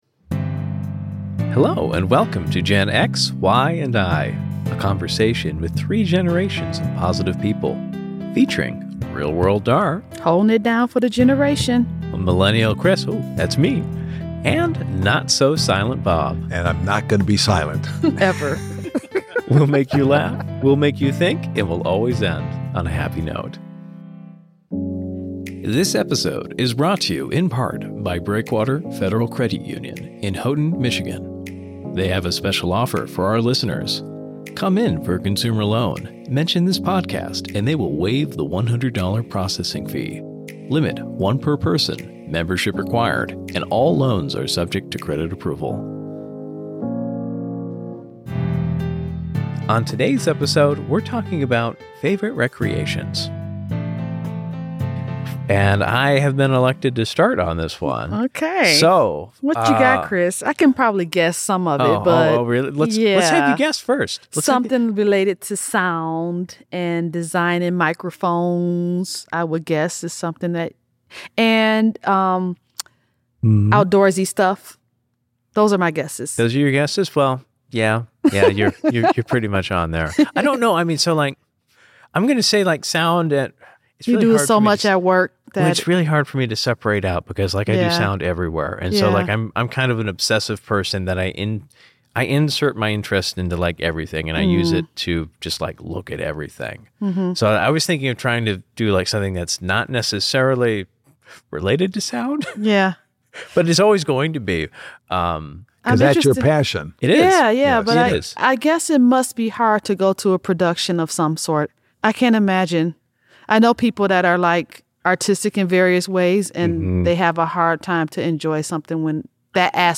🤔 This multi-generational discussion might answer that question.